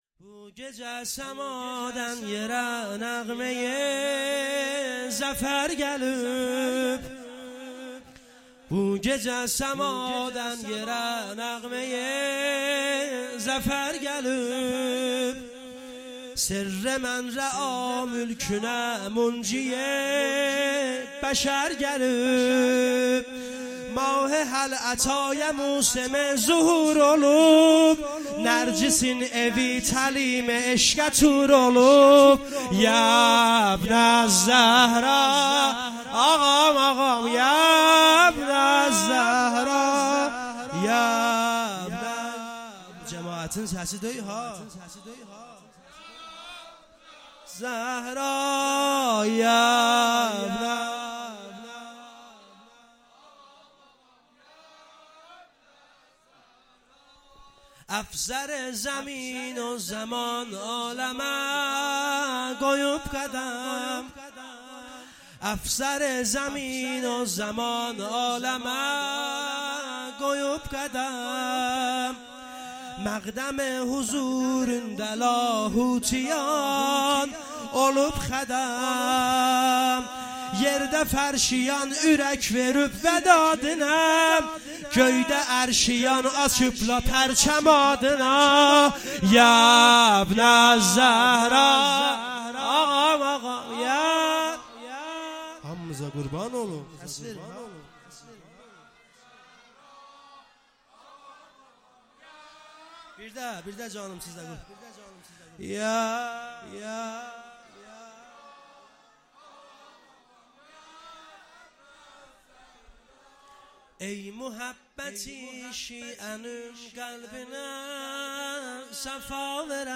گزارش صوتی جشن نیمه شعبان 1446